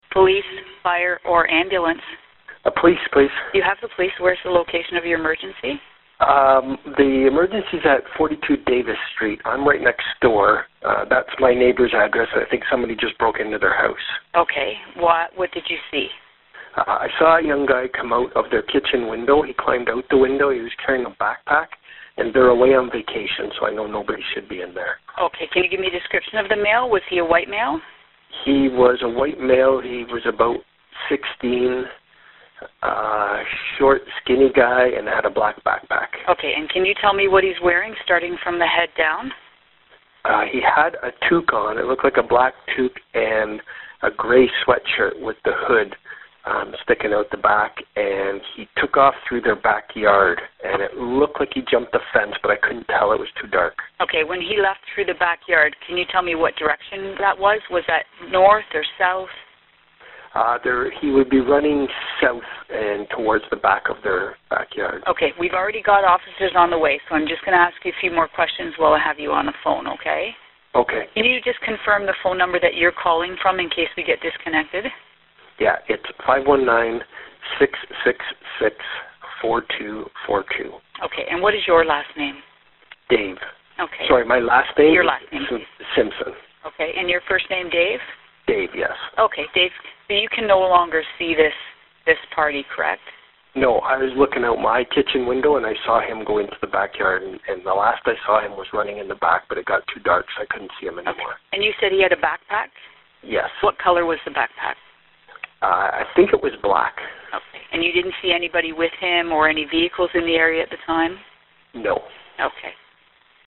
Here is an example of a full 9-1-1 call:
911 Call 2